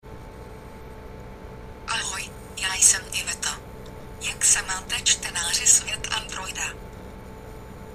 Svox Classic Text To Speech Engine
Female Czech voice for SVOX